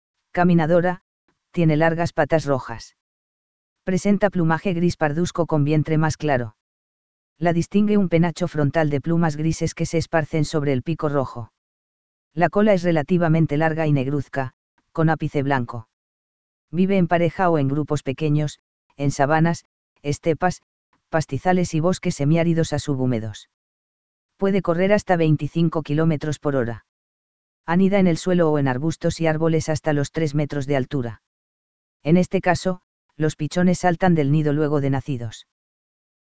Seriema.mp3